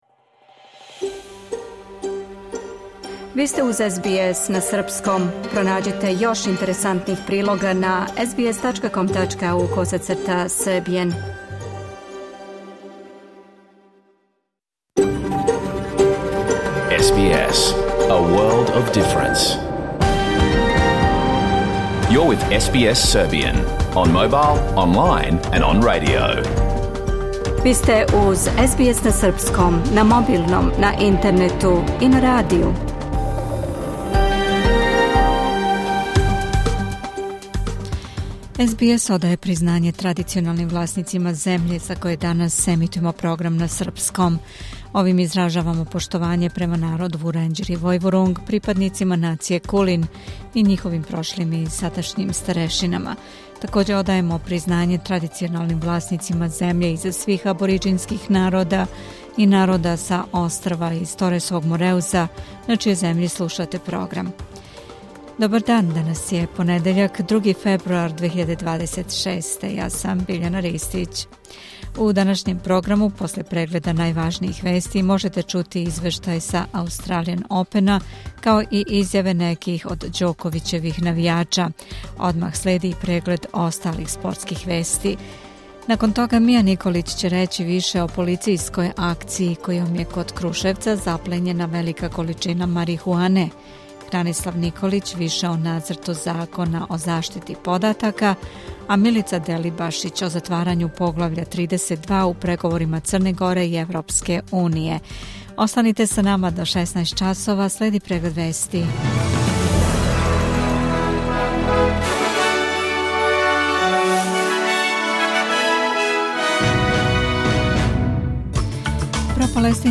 Преглед вести за 2. фебруар 2026. годинe